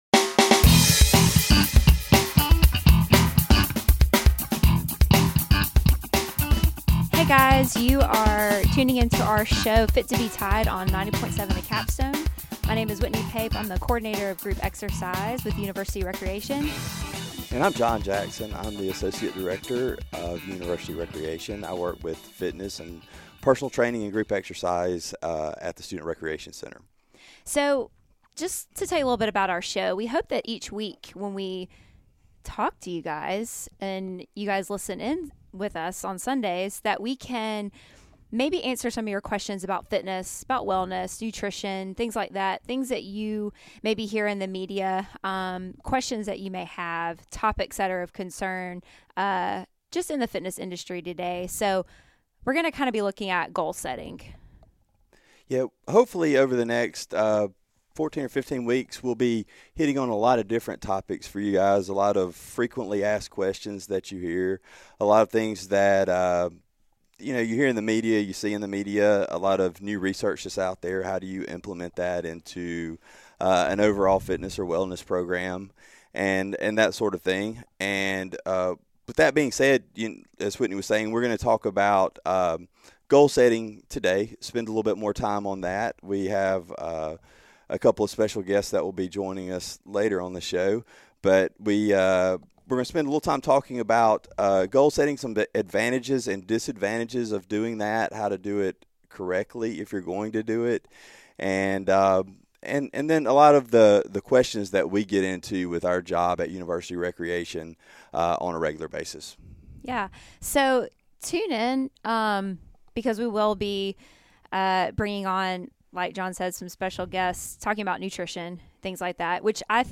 Originally aired 01/31/2016 on WVUA 90.7 FM, Tuscaloosa, AL.